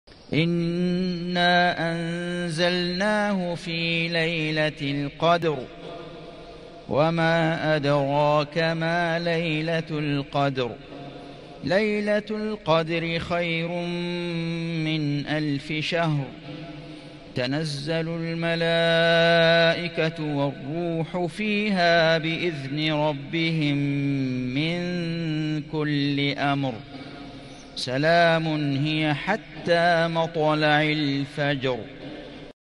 سورة القدر > السور المكتملة للشيخ فيصل غزاوي من الحرم المكي 🕋 > السور المكتملة 🕋 > المزيد - تلاوات الحرمين